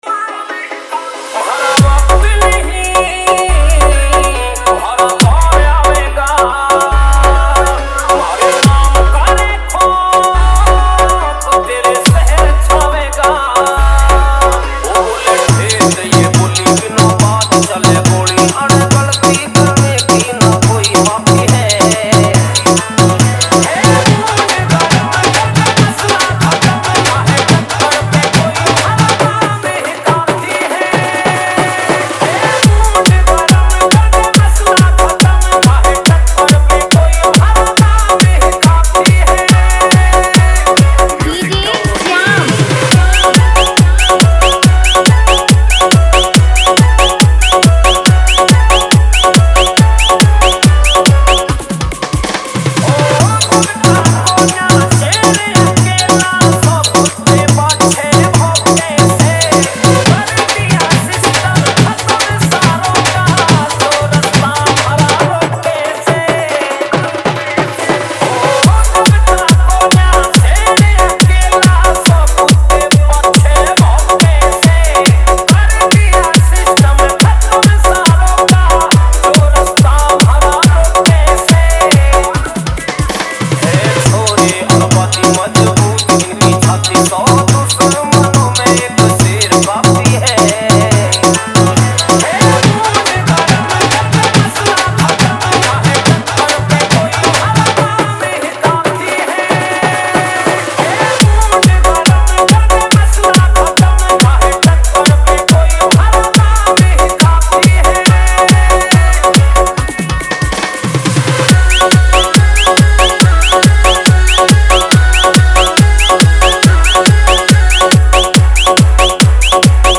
Album Haryanvi Remix Song (2024)